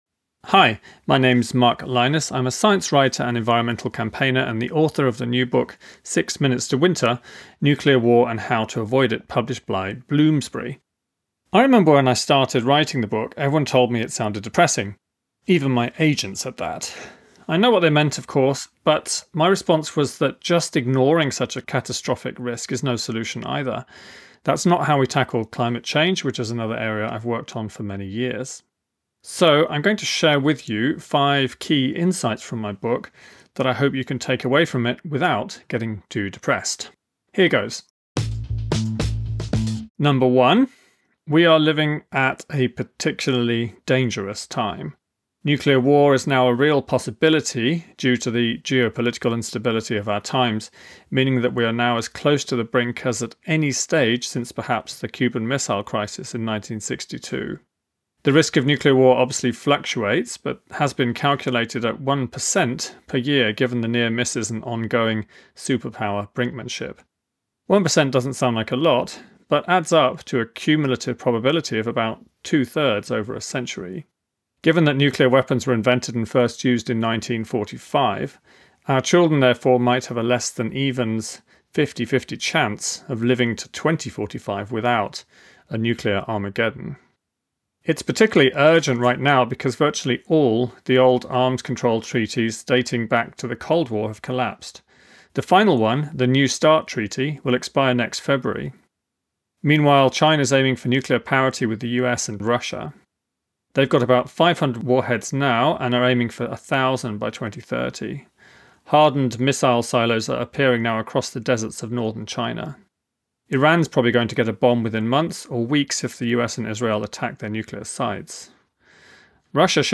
Listen to the audio version—read by Mark himself—in the Next Big Idea App.